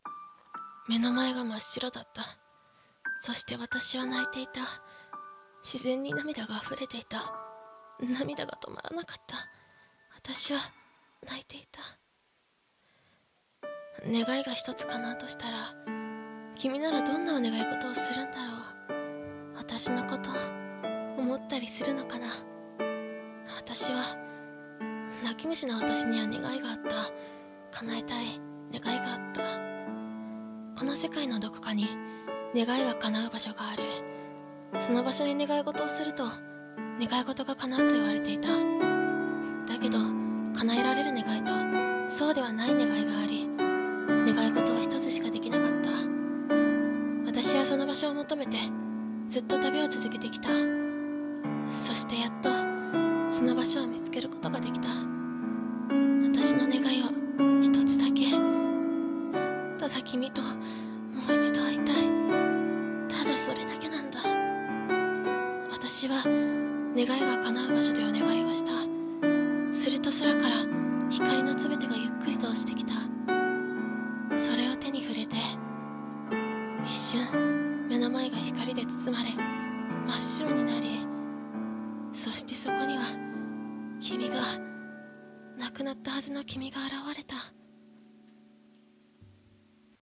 声劇「願いが叶う場所」